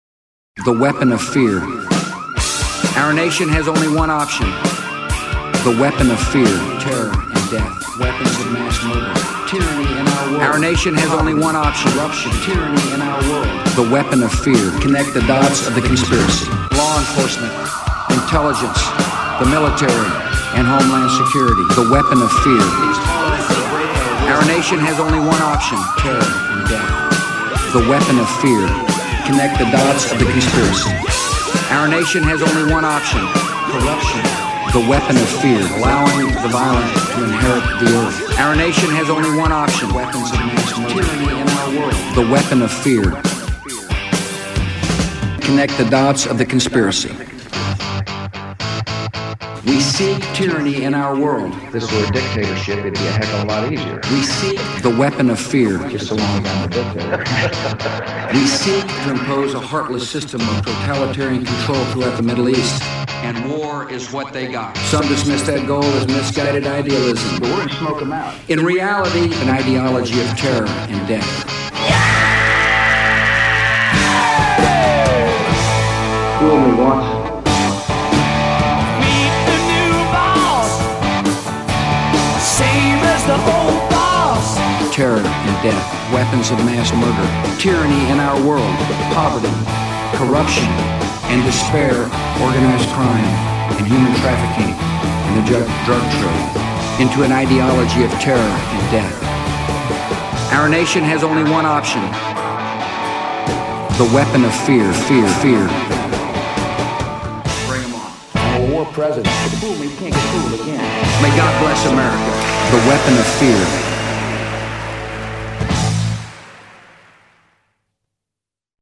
Source Material: State of the Union Address, January 31, 2006, with a few other popular quotes thrown in flavor. Soundtrack by The Cult, King Missile, and The Who.